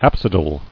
[ap·si·dal]